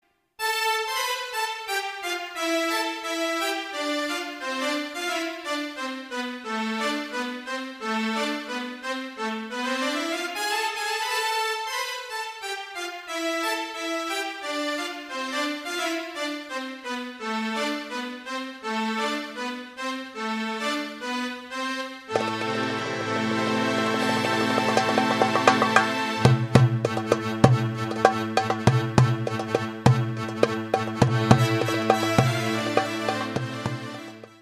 Dance classic. Lots of stops and changes.